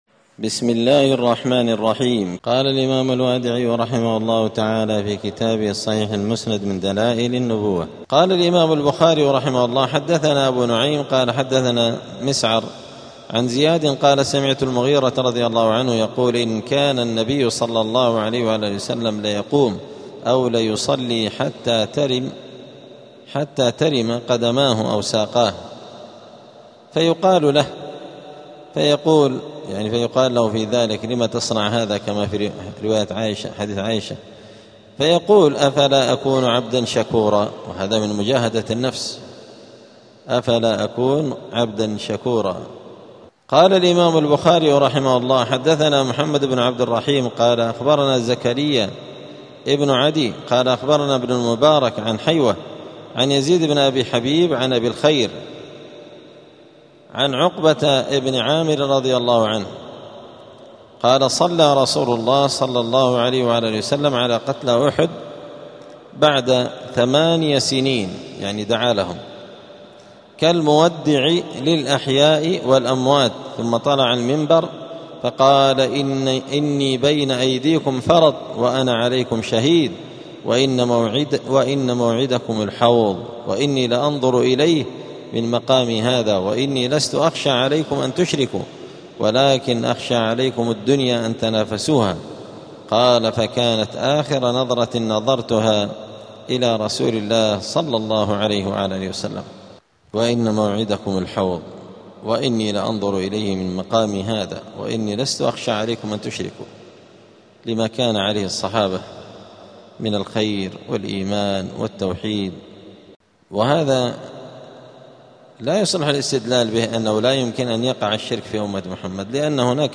*الدرس الثاني عشر (12) {تابع فصل في صفة رسول صلى الله عليه وسلم الخَلْقِية والخُلُقِية}.*